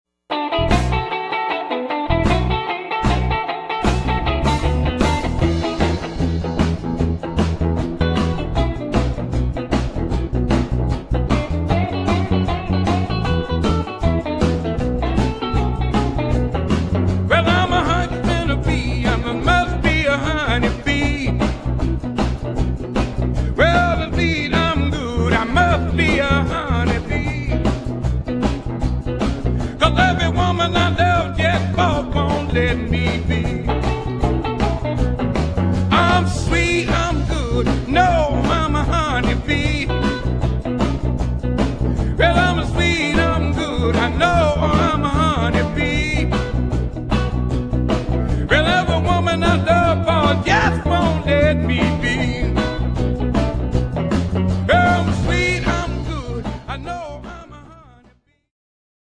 Chicago blues
guitar & vocals
guitar & harmonica
guitar, keyboard & vocals
bass
drums